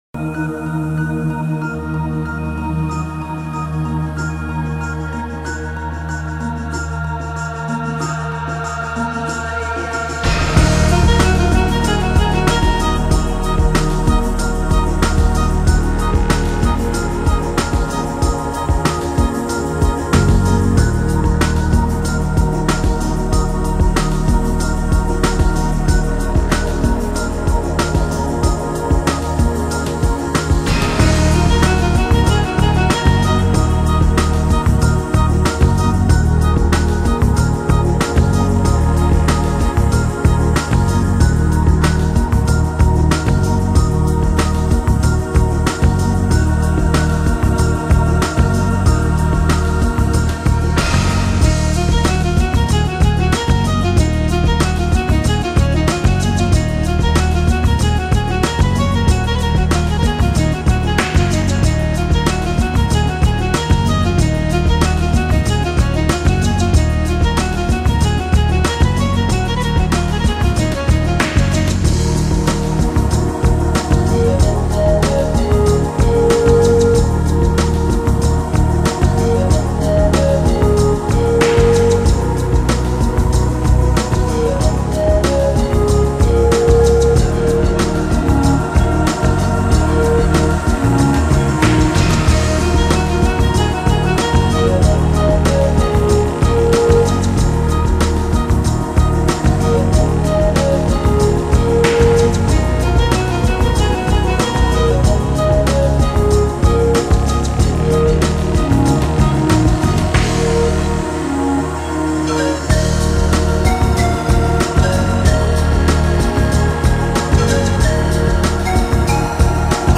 那些音乐结合了爱尔兰传统的民间乐器，例如口簧的呼啸声和uilleann 管，以及钢琴，还有电子乐、鼓乐。